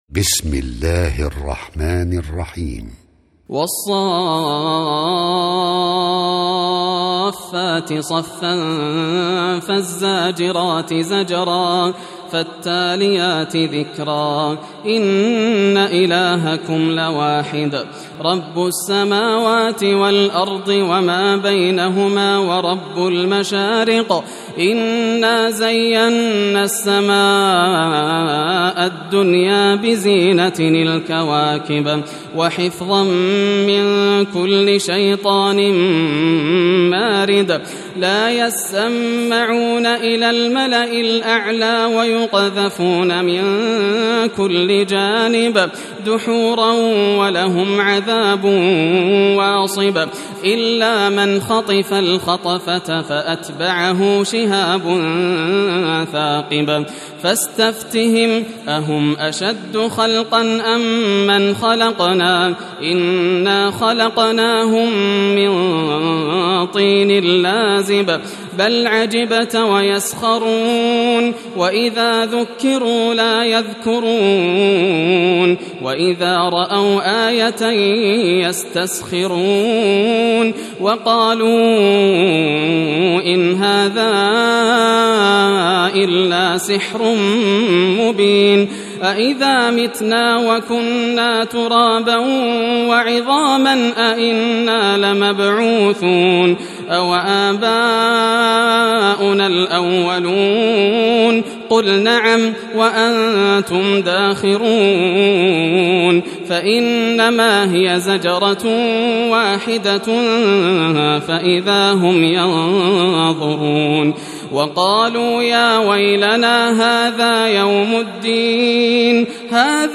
سورة الصافات > المصحف المرتل للشيخ ياسر الدوسري > المصحف - تلاوات الحرمين